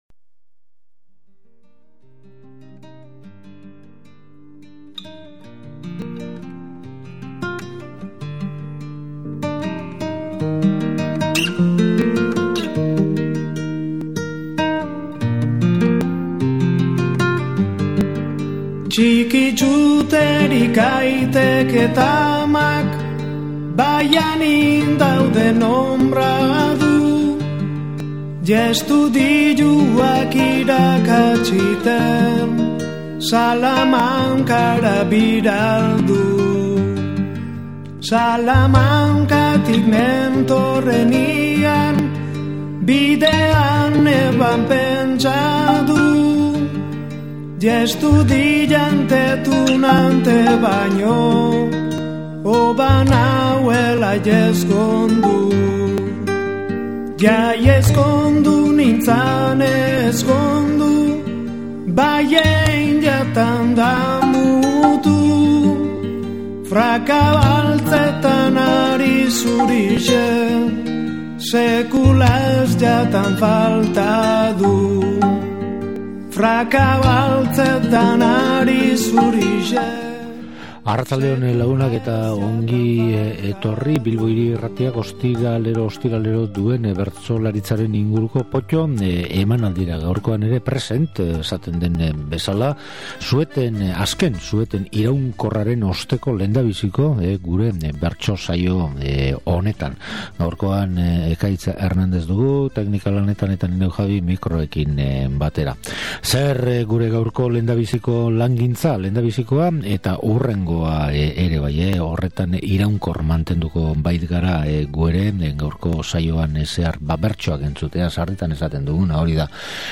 POTTO: Iazko bertso afari bat Bilboko Kafe Antzokian
Lagun ugari bildu ziren afari hartara eta bertsolariak ederto moldatu ziren afalosteko giroa berotzen.